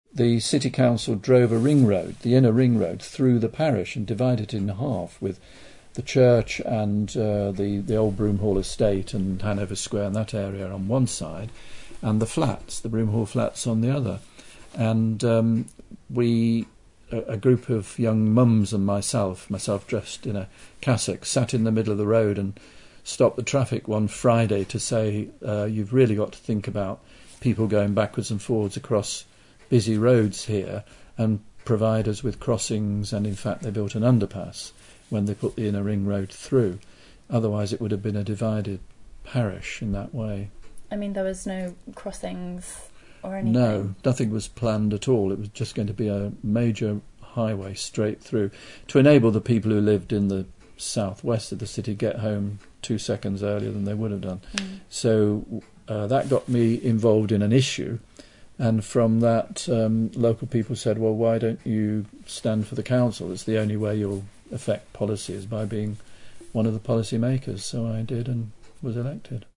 Underneath, you can listen to Alan Billings, the vicar of St Silas Church during the 1970s, discussing the sit-down protest with local mums that led to his career in local politics.